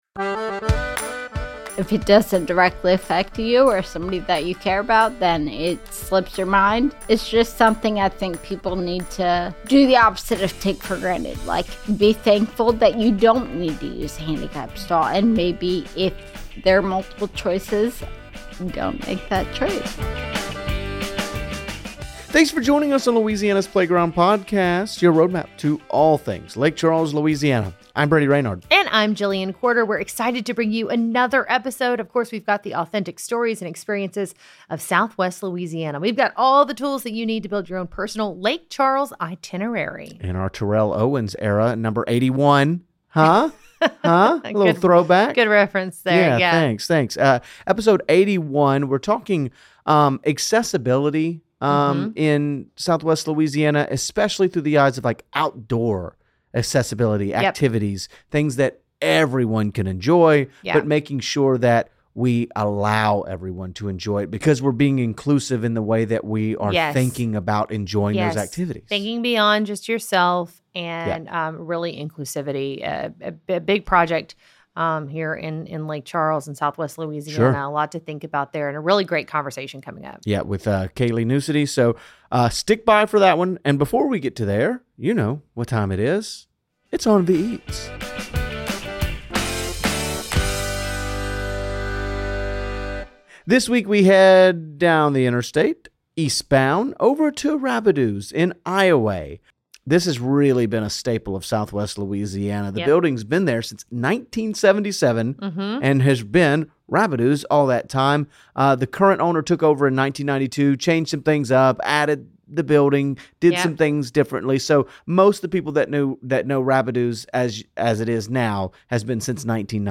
joins hosts